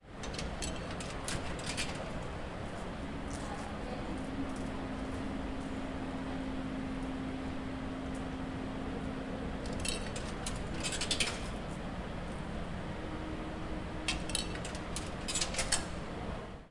购物中心停车场 " 付费机
描述：一个人在停车场付款（投币）的声音.